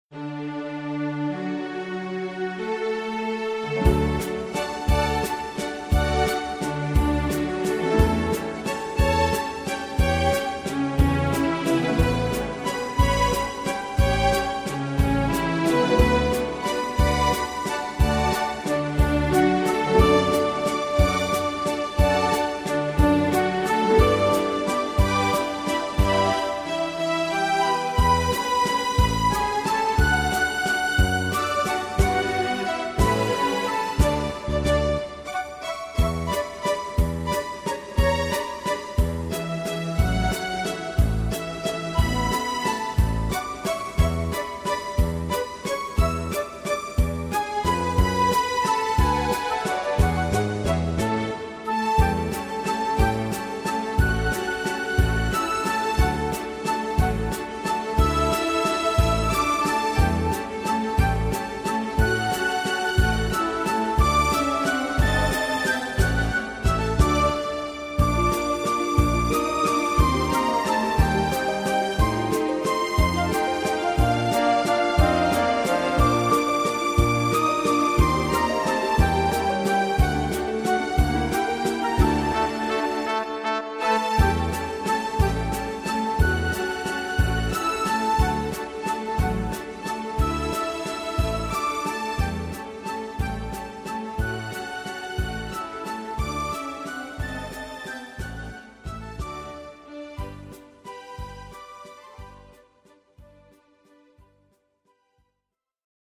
Donau-Walzer-Live.MP3